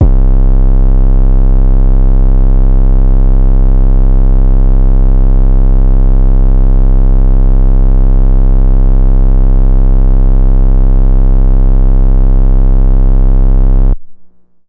808s
DECAP_808_ratchet_ssl_C.wav